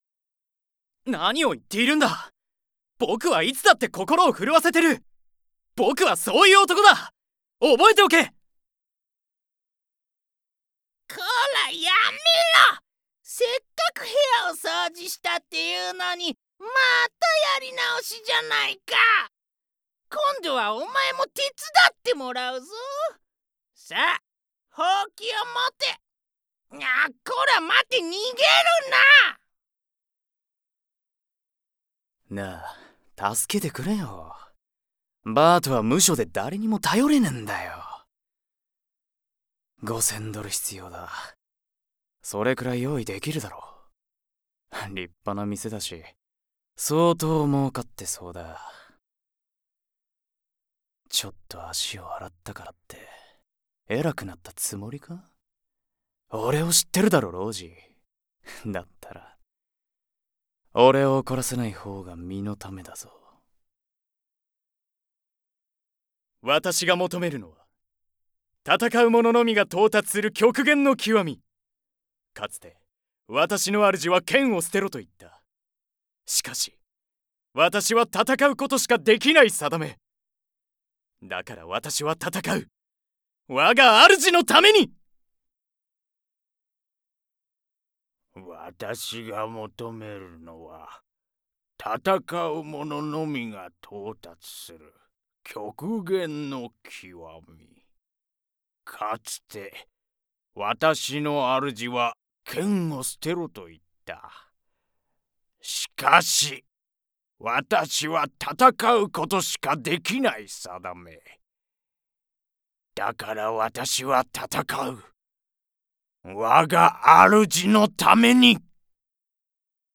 番組ナレーション①